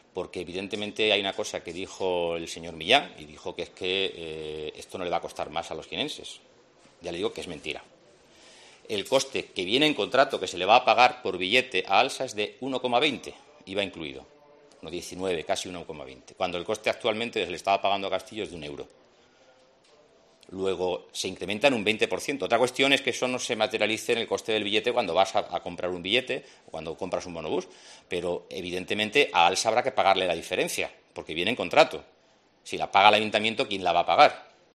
El portavoz del Grupo Municipal del PP de Jaén asegura que "es mentira" que el nuevo servicio de bus urbano costará lo mismo que antes.